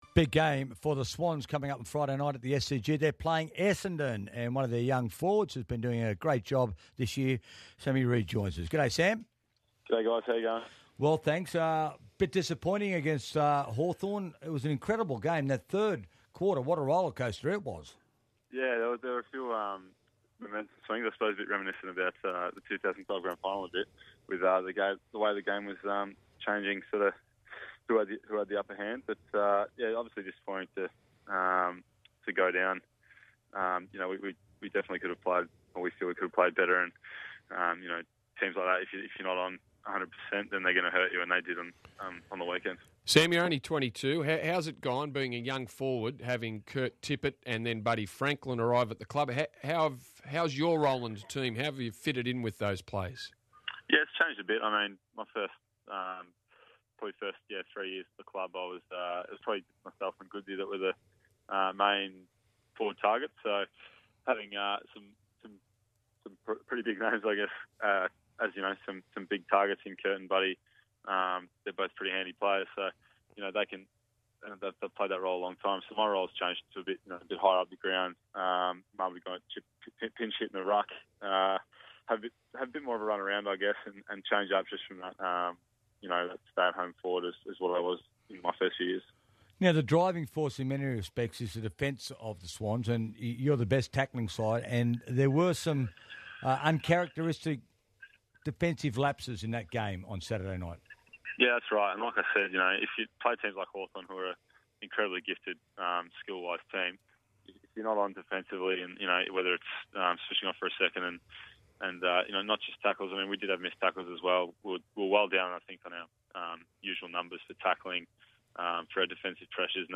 Sydney Swans forward Sam Reid appeared on 2UE's Sports Today program on Thursday July 31, 2014